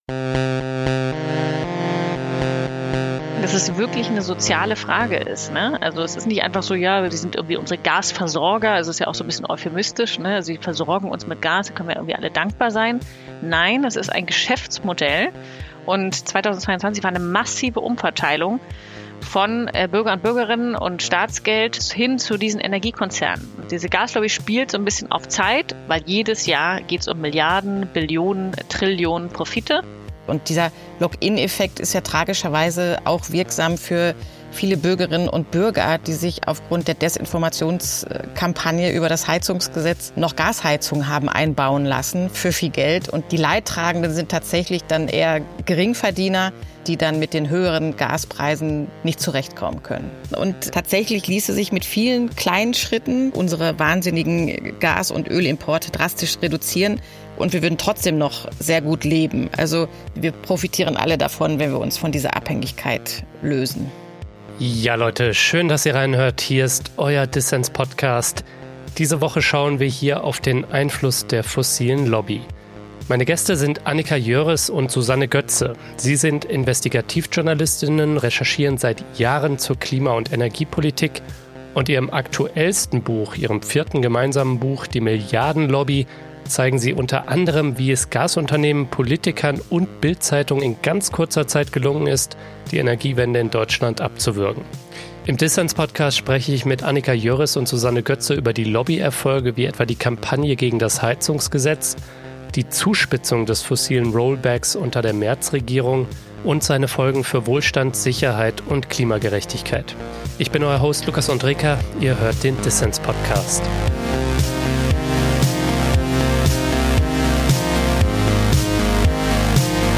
Ein Gespräch über die Zuspitzung des klimapolitischen Rollbacks unter der Merz-Regierung und die Folgen der fossilen Abhängigkeit für Wohlstand, Sicherheit und unsere Lebensgrundlagen.